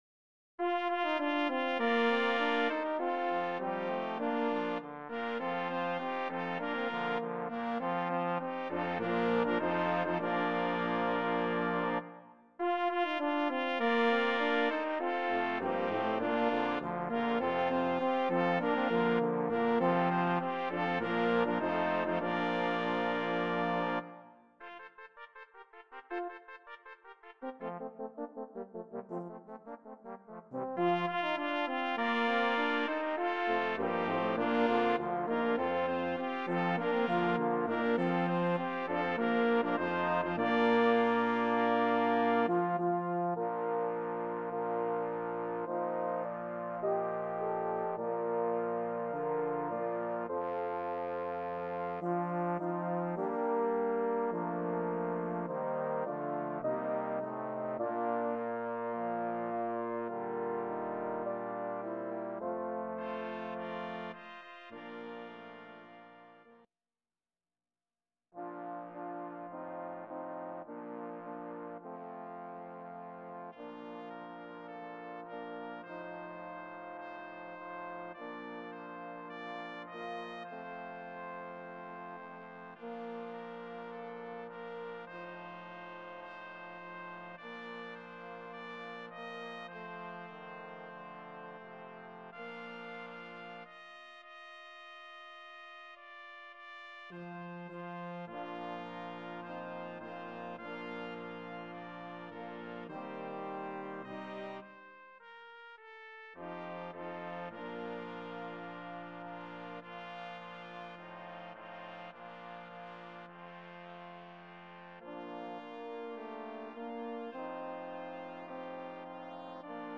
Voicing: Brass Ensemble